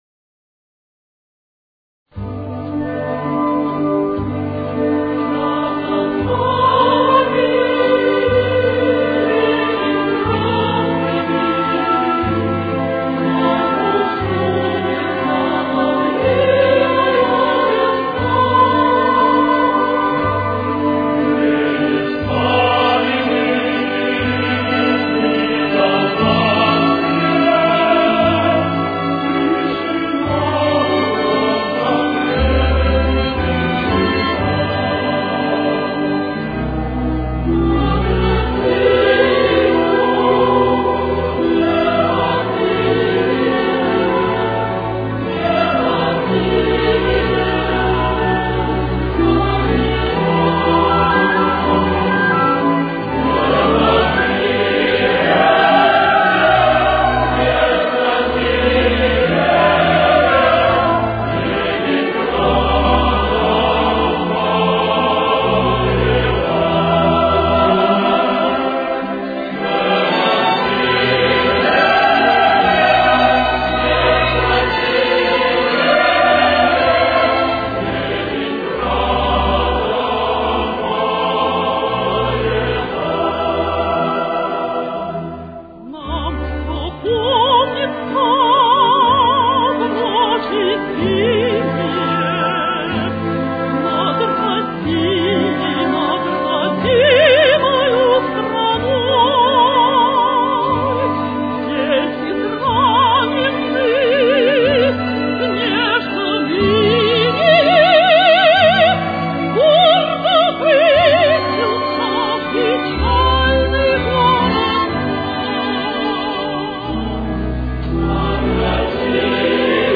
Соль мажор. Темп: 60.